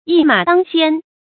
注音：ㄧ ㄇㄚˇ ㄉㄤ ㄒㄧㄢ
一馬當先的讀法